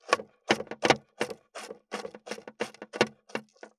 541包丁,厨房,台所,野菜切る,咀嚼音,ナイフ,調理音,まな板の上,料理,
効果音厨房/台所/レストラン/kitchen食器食材